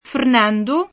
Fernando [fern#ndo; port.